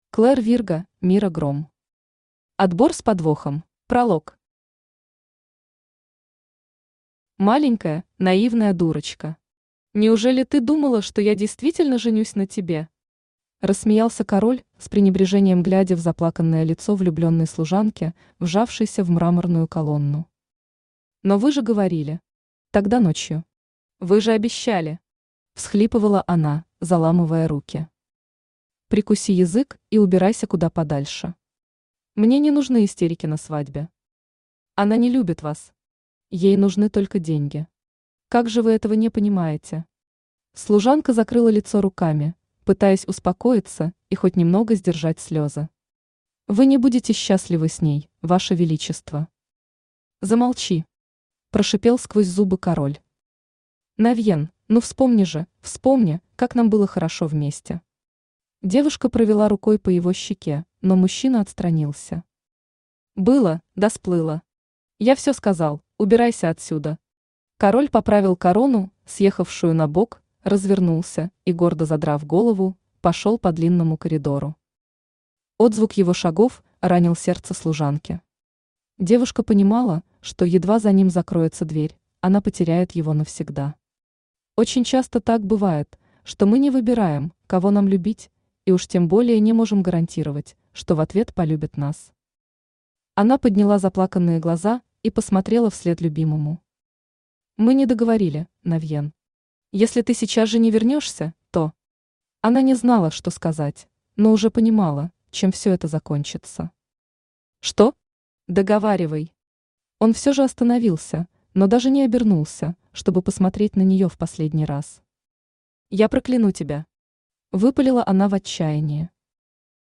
Аудиокнига Отбор с подвохом | Библиотека аудиокниг
Aудиокнига Отбор с подвохом Автор Клэр Вирго Читает аудиокнигу Авточтец ЛитРес.